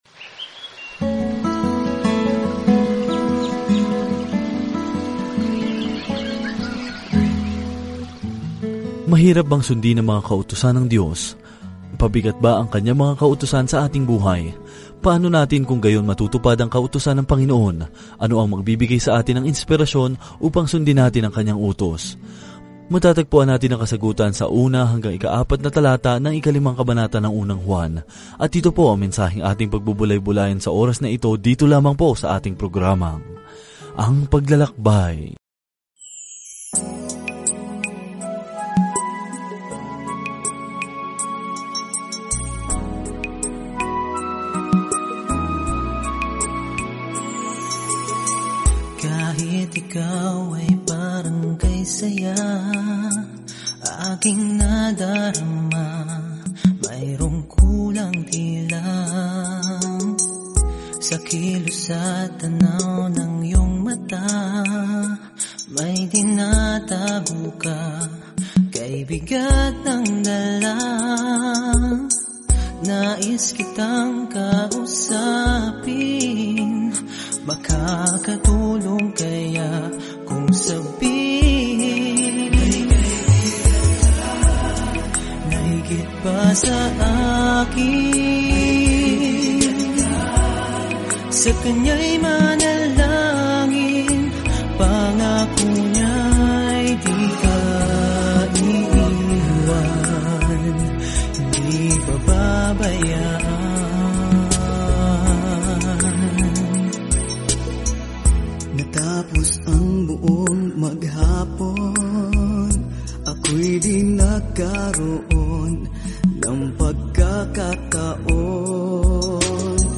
Banal na Kasulatan 1 Juan 5:1-4 Araw 21 Umpisahan ang Gabay na Ito Araw 23 Tungkol sa Gabay na ito Walang gitnang lupa sa unang liham na ito mula kay Juan - piliin man natin ang liwanag o dilim, katotohanan sa kasinungalingan, pag-ibig o poot; niyakap natin ang isa o ang isa, tulad ng ating paniniwala o pagtanggi sa Panginoong Jesucristo. Araw-araw na paglalakbay sa 1 John habang nakikinig ka sa audio study at nagbabasa ng mga piling talata mula sa salita ng Diyos.